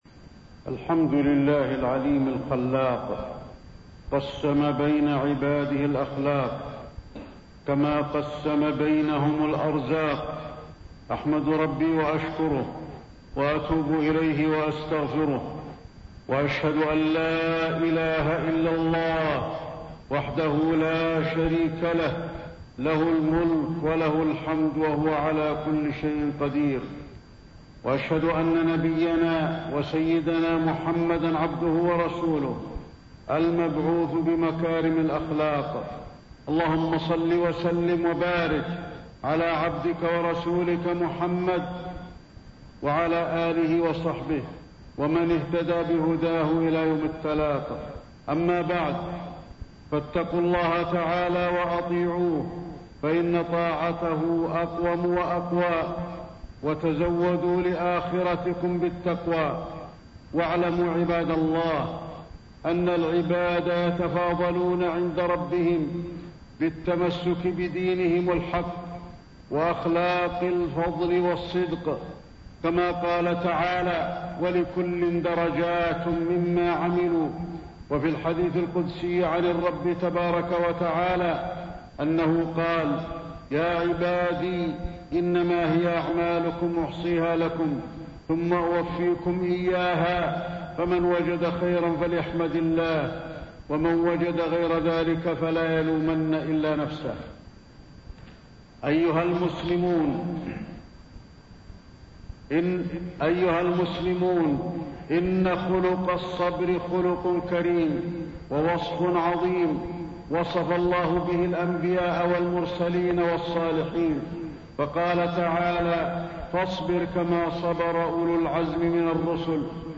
التصنيف: خطب الجمعة